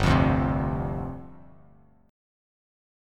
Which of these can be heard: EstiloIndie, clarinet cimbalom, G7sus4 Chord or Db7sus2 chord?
G7sus4 Chord